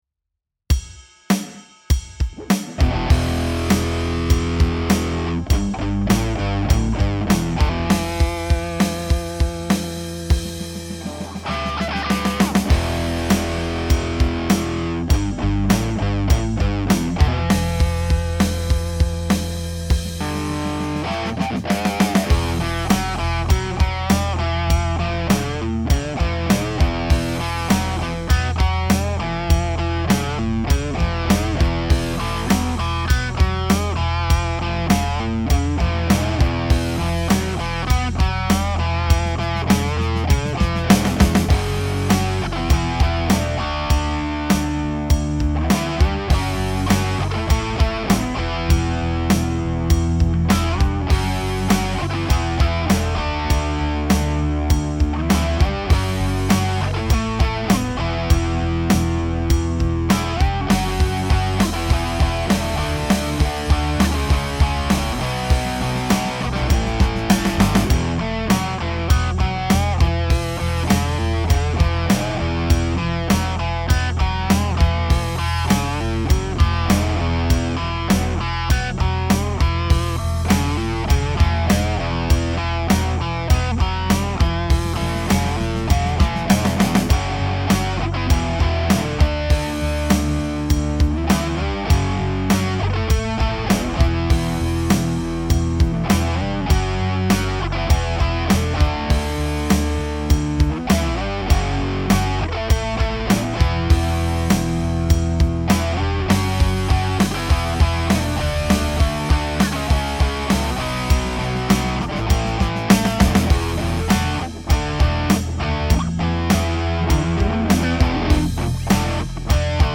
Lead Guitar
Rhythm Guitar
Bass Guitar
Drums